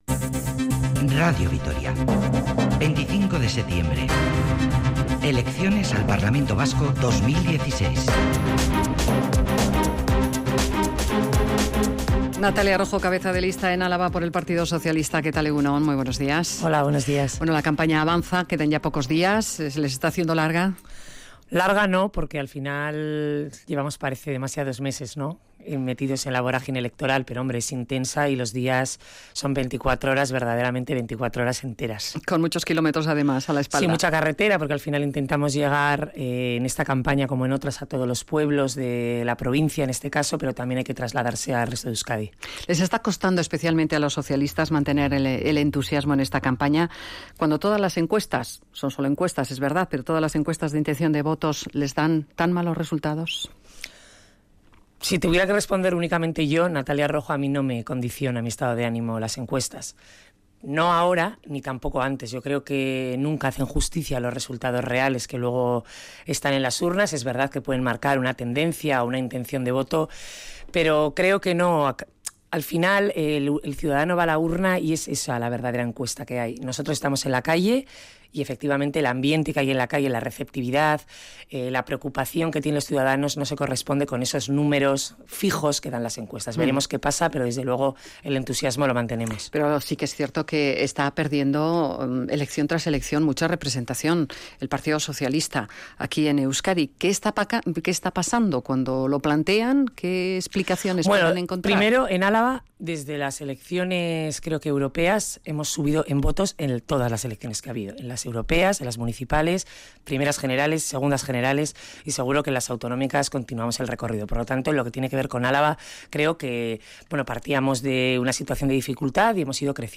Entrevista de la campaña electoral a la cabeza de lista del Partido Socialista de Euskadi por Álava, Natalia Rojo.